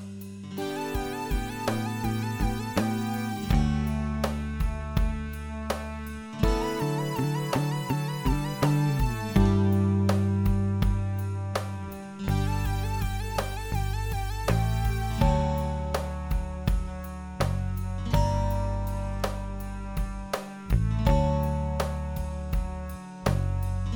Minus Electric Guitars Pop (1970s) 5:18 Buy £1.50